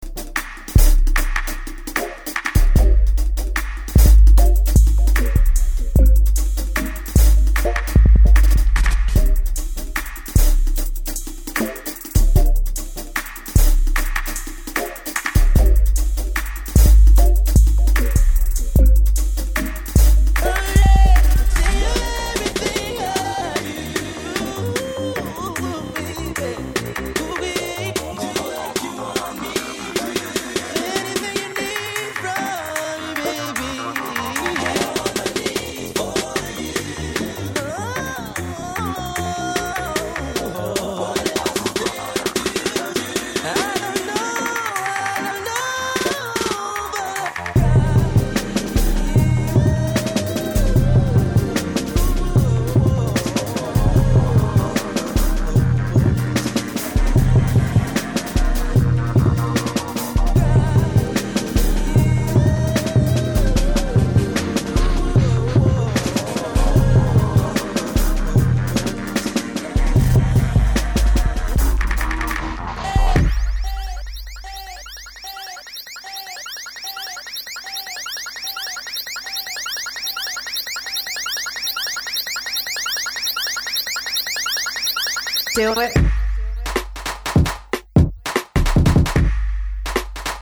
Future Bass/ 21st Century R&B